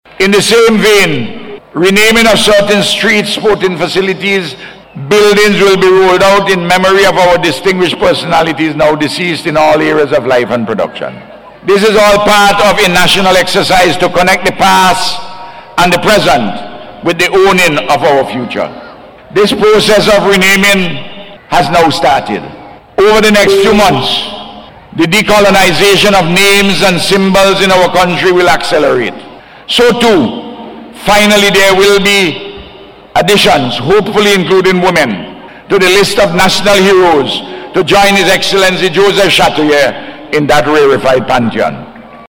Prime Minister Dr. Ralph Gonsalves made the announcement during his Independence Day Address at the Military Parade last week.